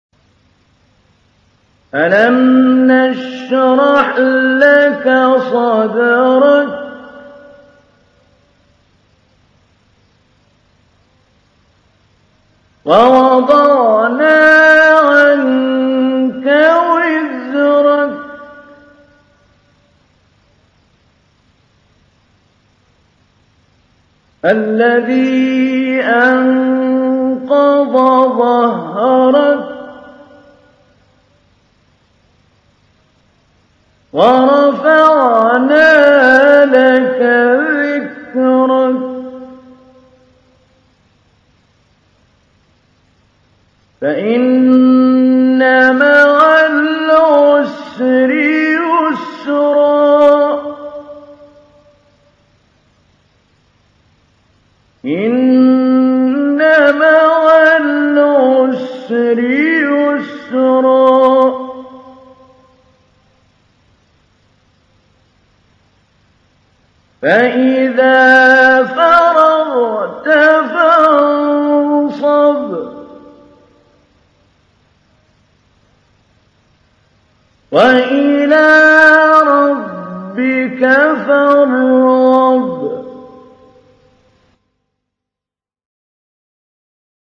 سورة الشرح | القارئ محمود علي البنا